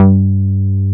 R MOOG G3MF.wav